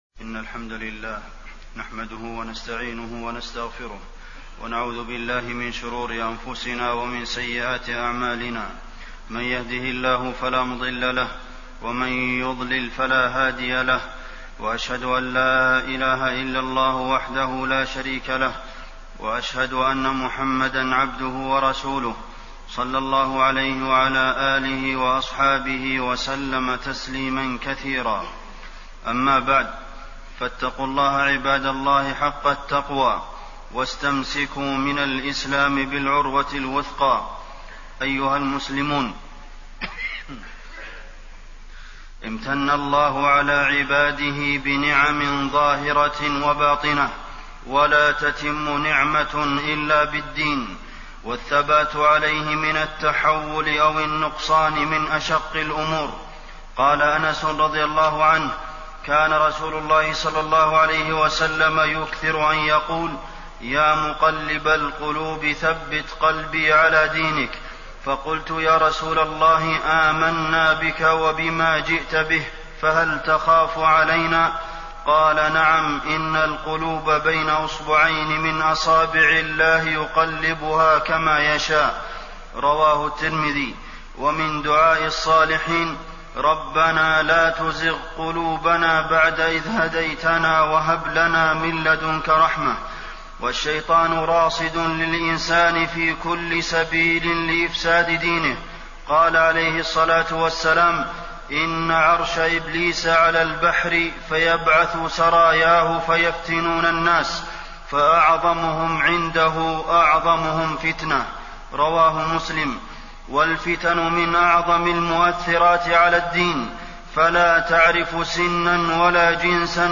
تاريخ النشر ١٥ رجب ١٤٣٢ هـ المكان: المسجد النبوي الشيخ: فضيلة الشيخ د. عبدالمحسن بن محمد القاسم فضيلة الشيخ د. عبدالمحسن بن محمد القاسم التحذير من الفتن